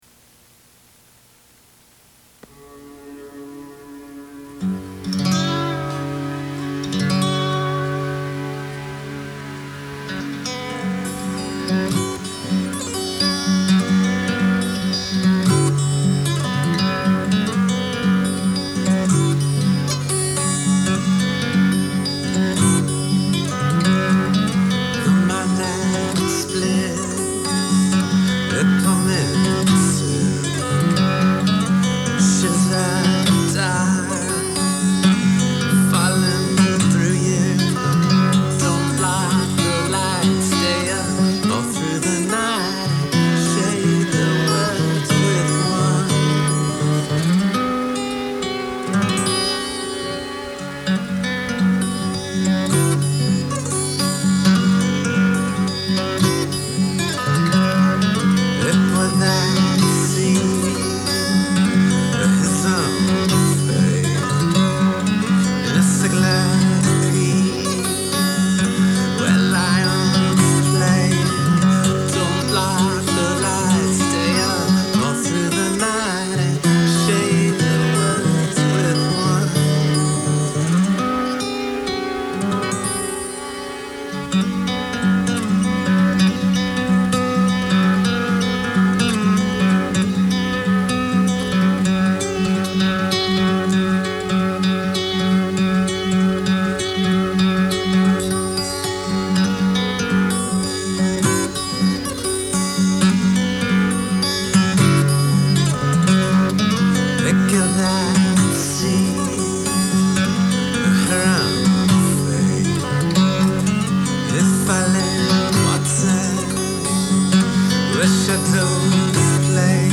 divine droning devotionals to the luminous void
the chiming, charge of guitar that opens the song
the song drifts toward domineering drone